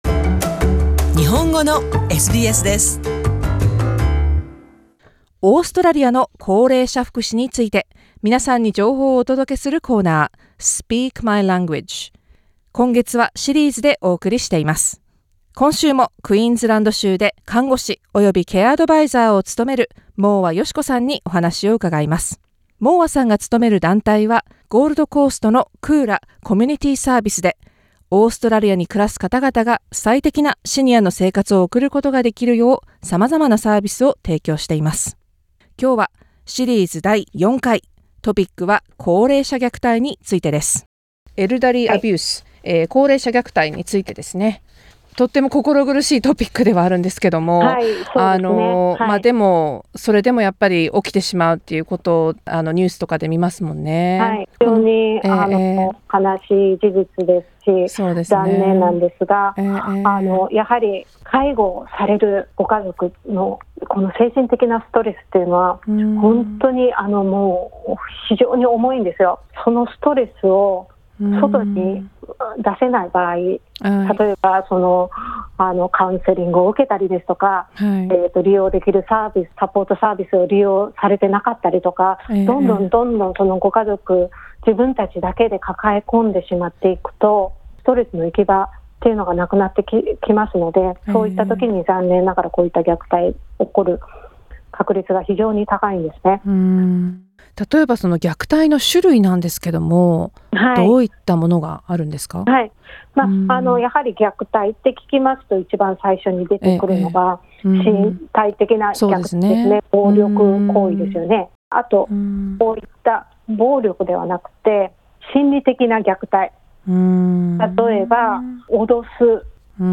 Ethnic Community Council of New South Walesのイニシャティブのもと始まり、SBSが放送パートナーを務めています。